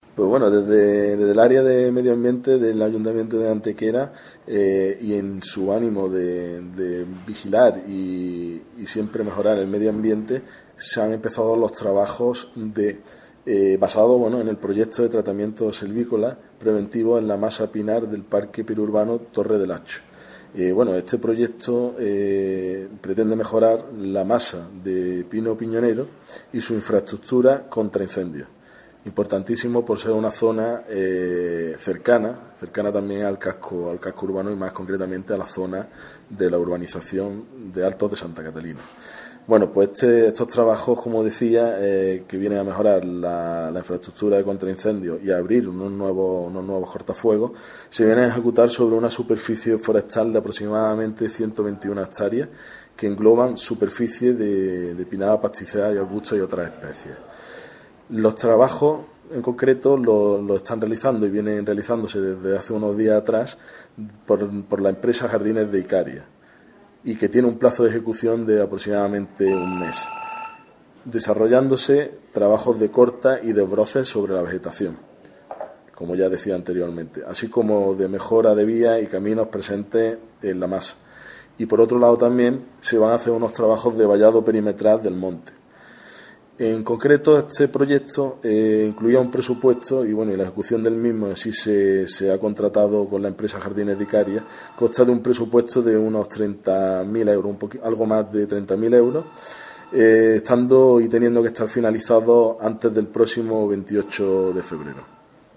Cortes de voz J. Alvarez 723.78 kb Formato: mp3